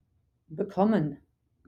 to receive , to et bekommen (be-KOMM-en)